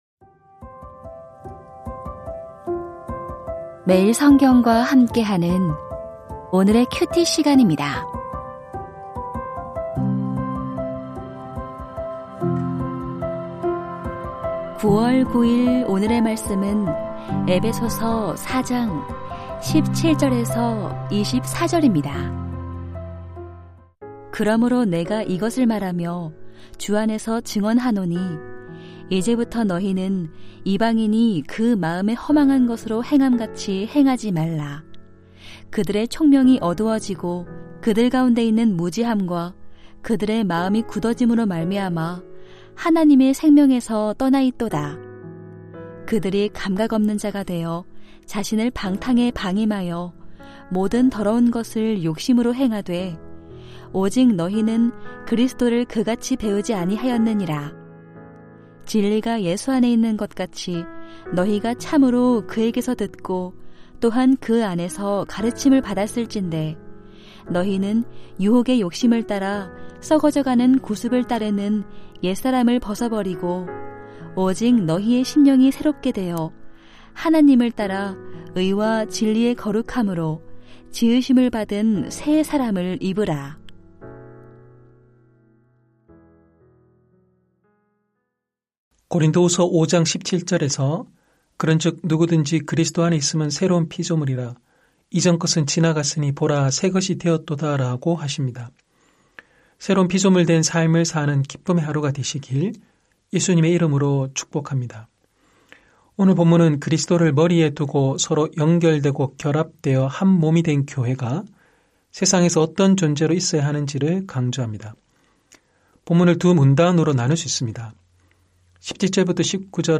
에베소서 4:17-24 버릴 것, 벗을 것, 입을 것 2022-09-09 (금) > 오디오 새벽설교 말씀 (QT 말씀묵상) | 뉴비전교회